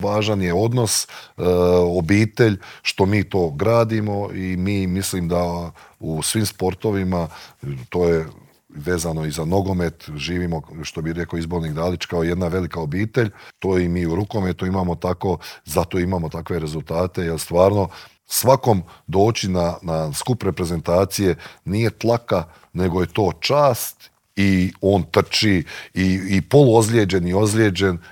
Nakon svjetskog srebra svi se nadaju ponovnom uzletu na smotri najboljih rukometaša starog kontinenta, a o očekivanjima, željama, formi te o tome zašto se u sportu nema strpljenja s trenerima i izbornicima te kako su se nekada osvajale svjetske i olimpijske i klupske titule u Intervjuu Media servisa razgovarali smo bivšim reprezentativcem, legendarnim Božidarem Jovićem.